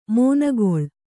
♪ mōnagoḷ